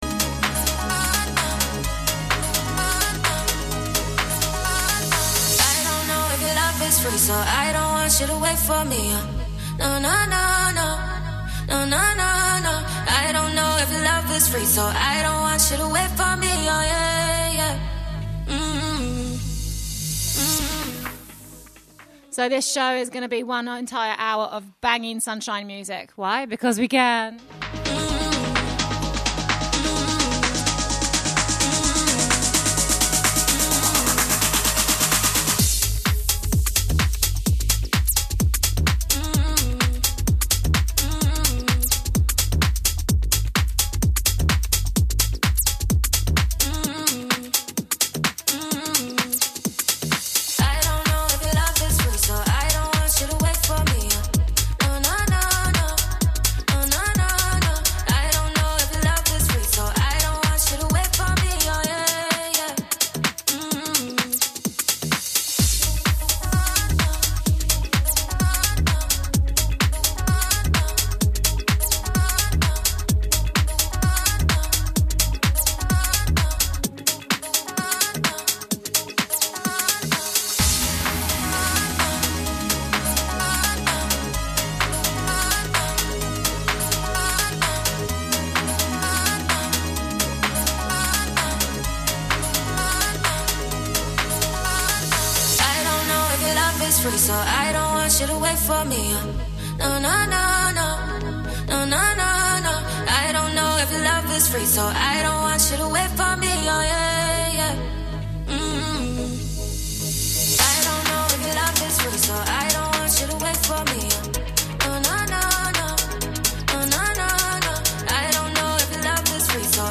HOUSE HOUR